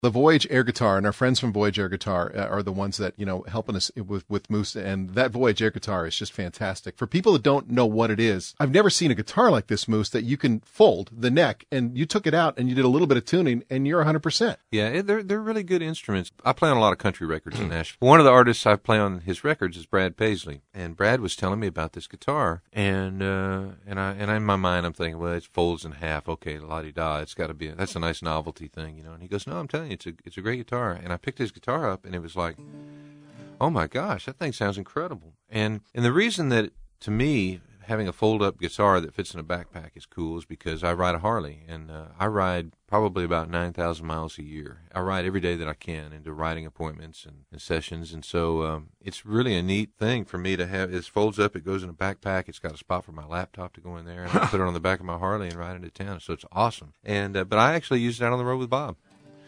Interview talks about Brad Paisley introducing him to Voyage-Air